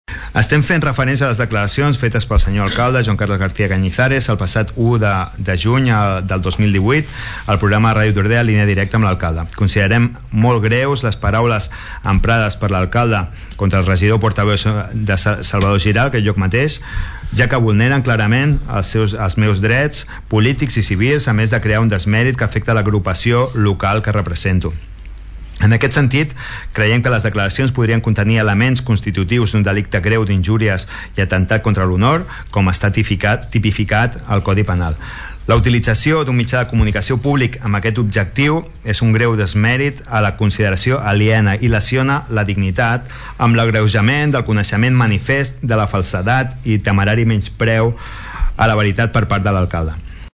El ple de l’Ajuntament va debatre ahir una moció presentada pel grup municipal de Som Tordera-Entesa en defensa dels drets polítics i civils de les persones, la democràcia i la llibertat d’expressió i en contra de les conductes que vulnerin aquests principis fonamentals.
El regidor de Som Tordera, Salvador Giralt va reclamar la llibertat d’expressió com un dret humà.